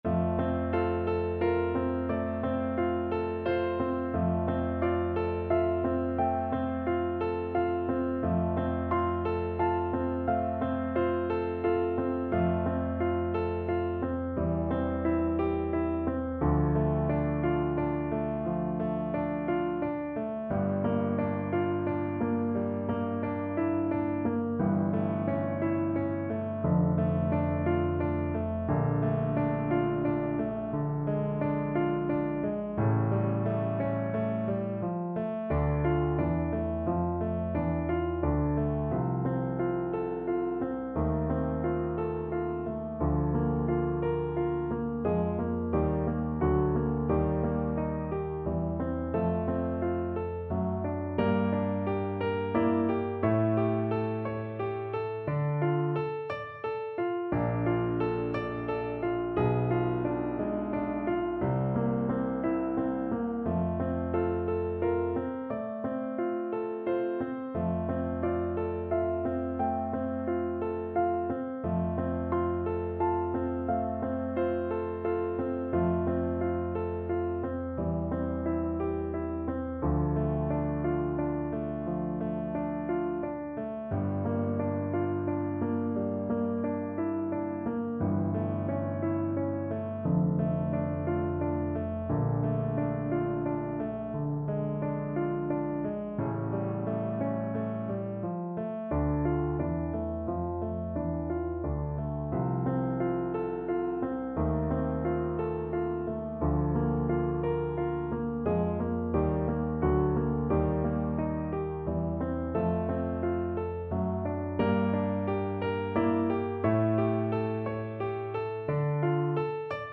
~ = 88 Andante
6/4 (View more 6/4 Music)
Classical (View more Classical French Horn Music)